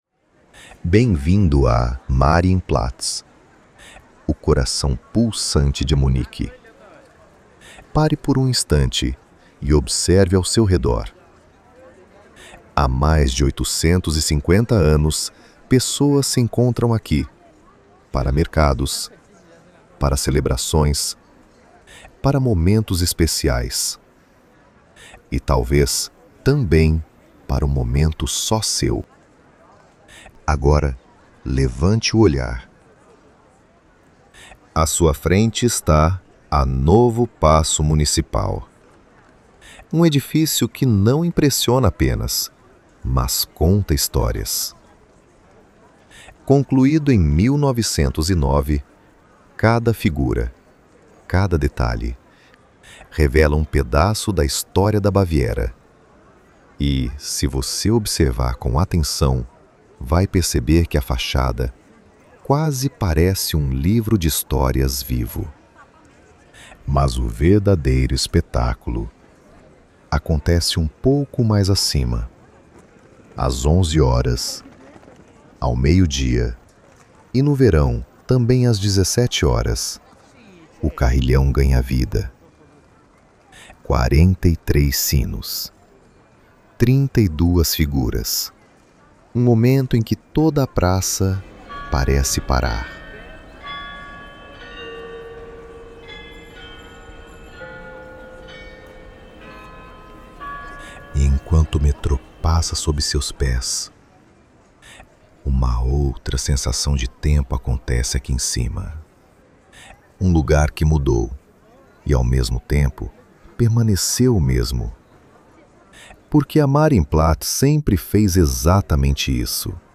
Audiofeature · Flagship
O ponto alto é o lendário Glockenspiel – primeiro descrito em detalhe, depois ouvido no original.
Conceito e script próprios como um audiofeature cinematográfico com uma estrutura dramatúrgica clara, pausas precisas, variações de ritmo e o Glockenspiel original como ponto culminante acústico. Design de voz de terceira geração de alta qualidade, quatro idiomas, qualidade consistente.